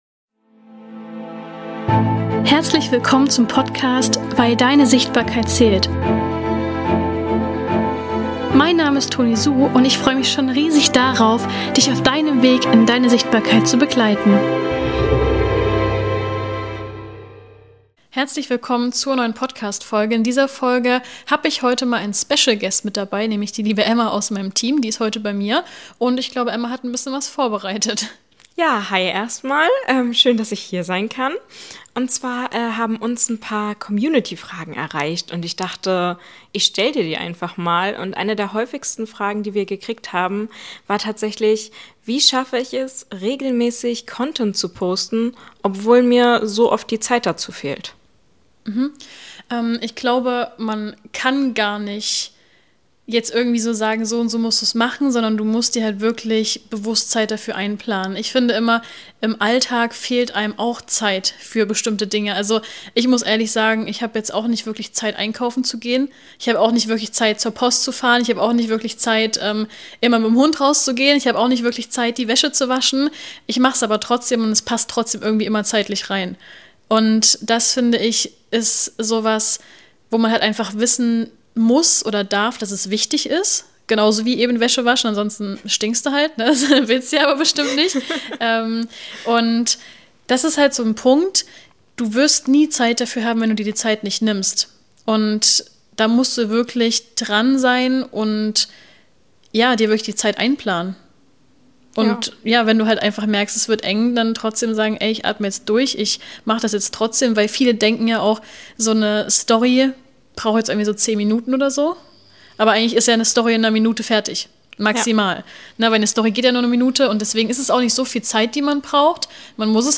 In dieser Q&A-Episode beantworten wir Eure Fragen rund um Sichtbarkeit, Posting-Routine & Mindset.